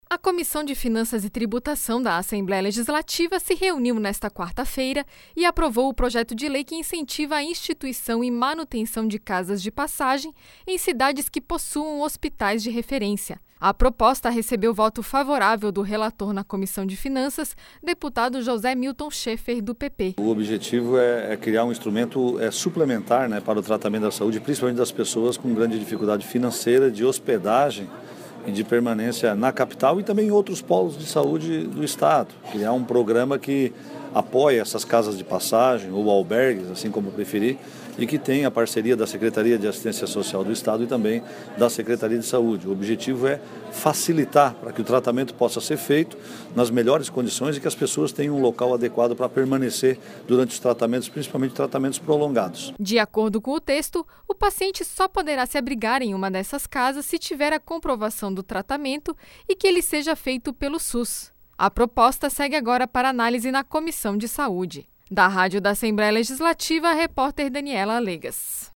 Entrevista com: deputado José Milton Scheffer (PP), relator da proposta na Comissão de Finanças.